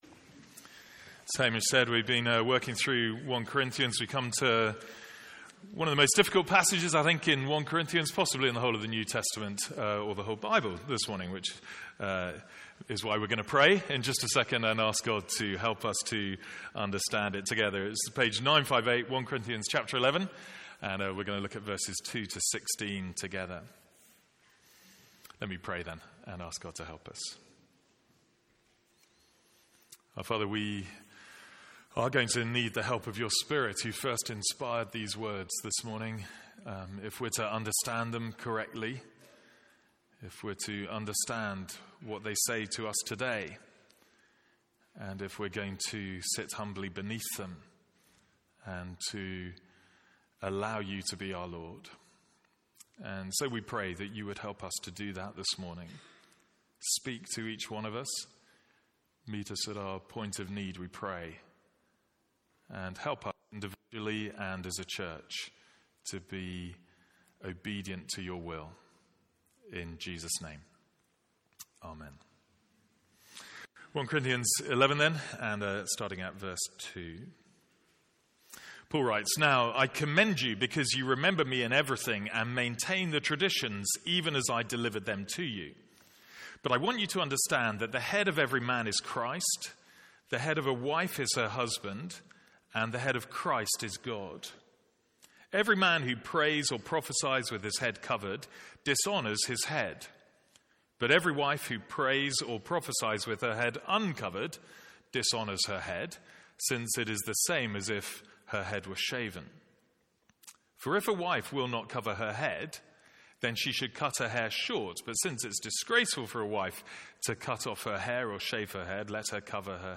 From our morning service in 1 Corinthians.